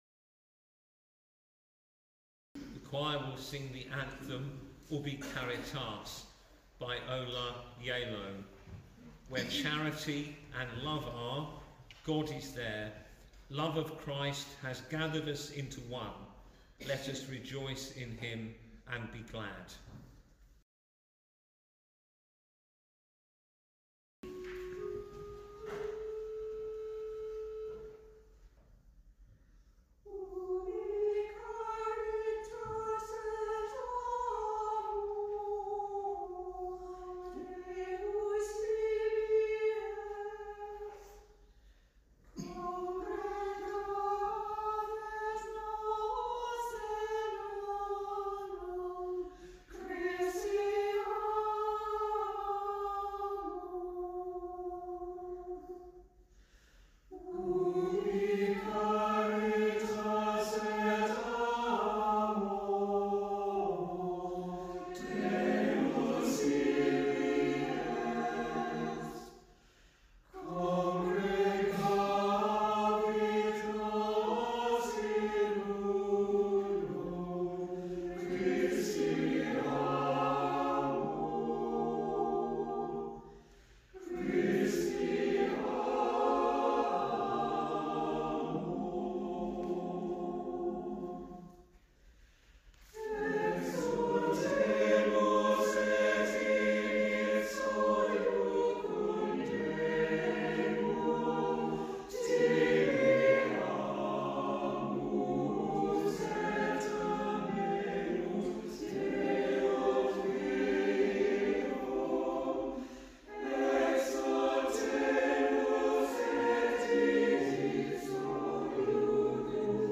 Deanery Choir
Here is a list of recordings from the choir: